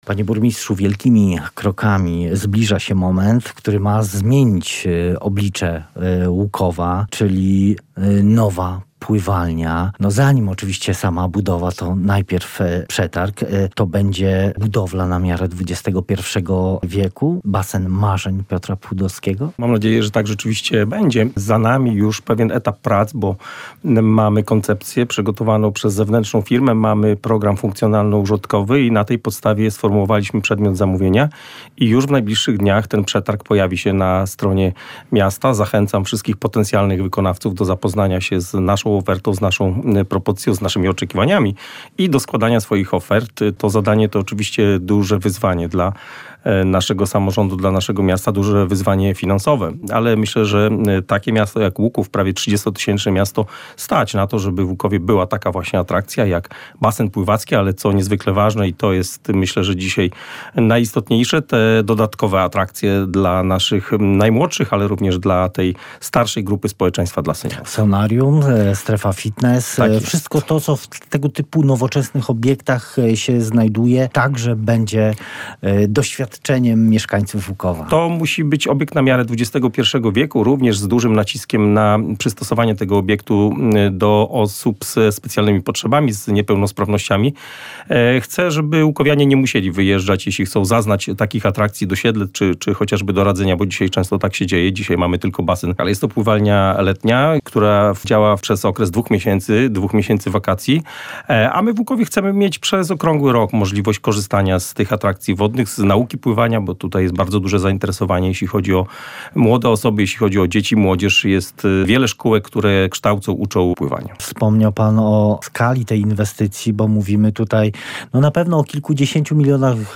z Piotrem Płudowskim, burmistrzem Łukowa rozmawia